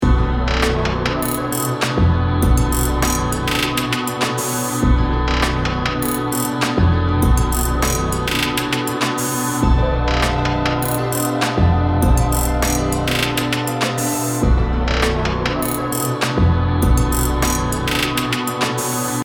528hz BPM100-109 Game Instrument Soundtrack インストルメント
BPM 100